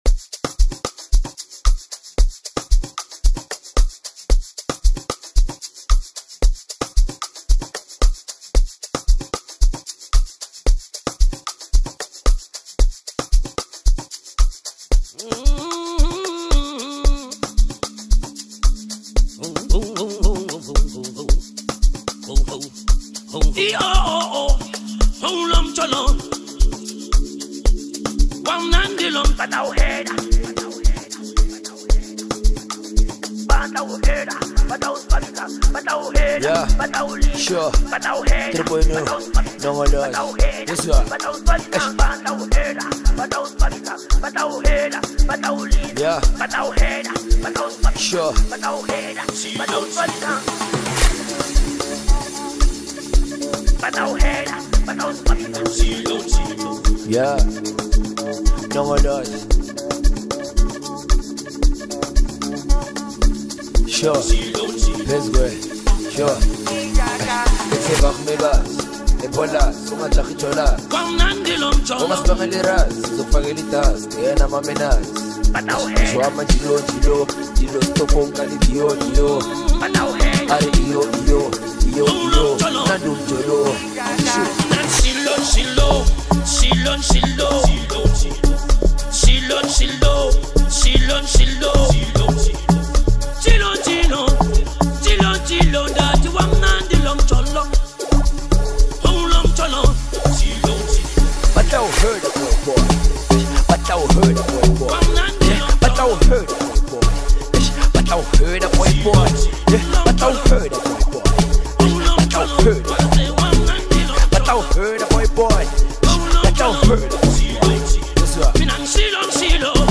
As usual the energy is strong on this track.